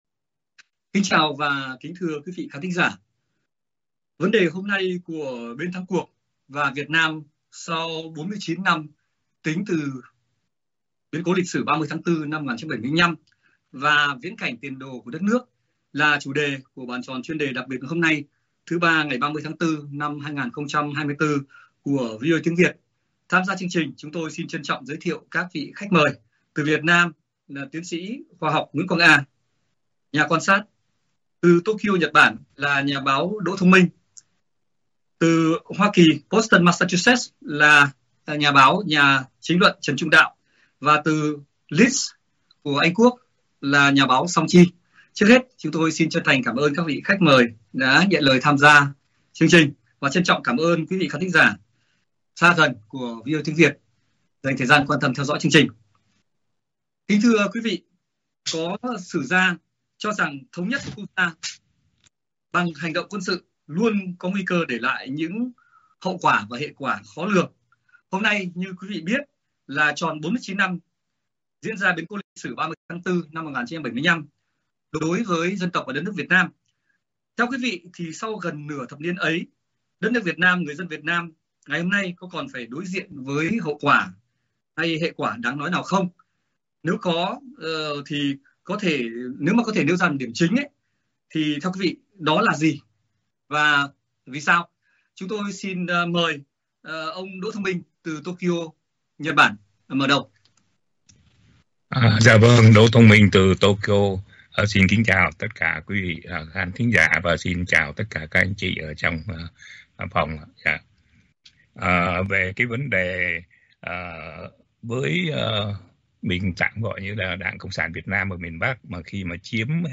Các nhà quan sát, phân tích chính trị, xã hội và nhà báo, nhà chính luận từ Việt Nam và hải ngoại thảo luận về hiện tình chính trị - xã hội Việt Nam và bàn về tương lai đất nước sau 49 năm biến cố lịch sử 30/4/1975.